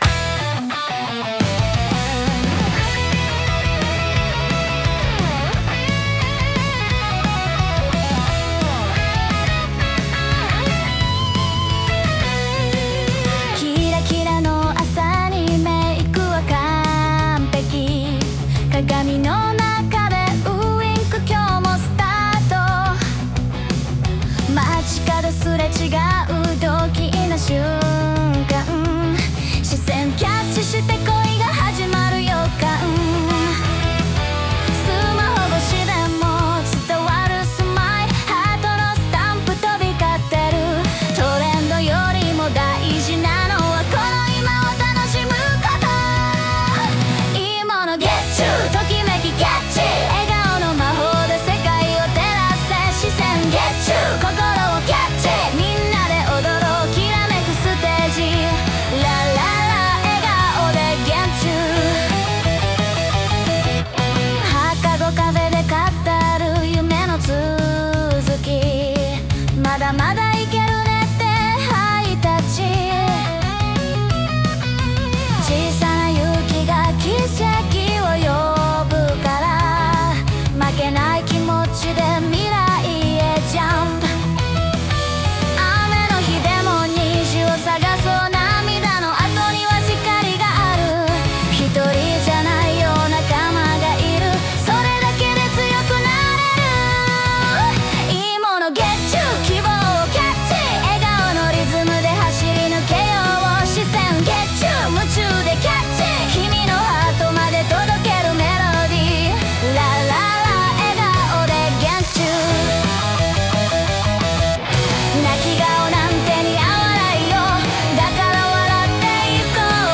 明るい気持ちになれるハッピーソング！